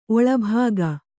♪ oḷabhāga